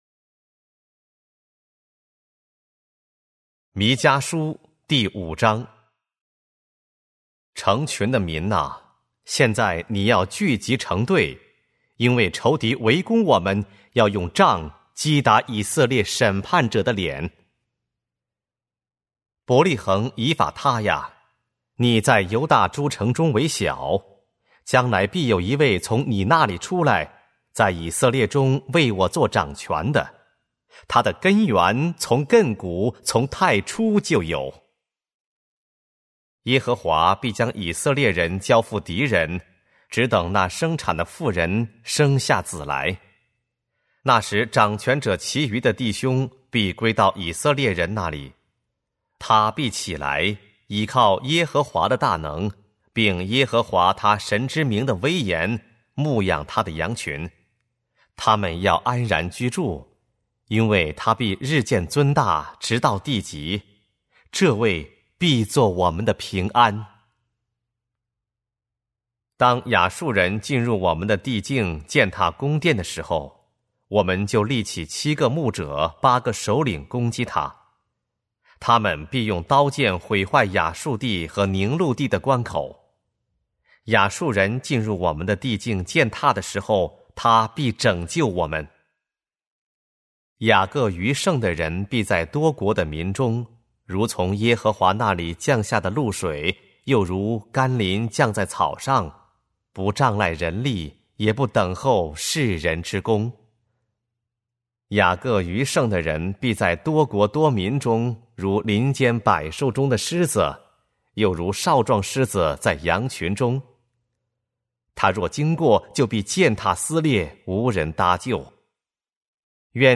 和合本朗读：弥迦书